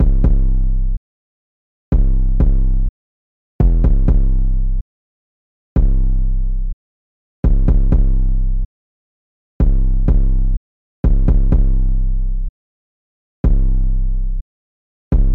Tag: 125 bpm Trap Loops Drum Loops 2.58 MB wav Key : C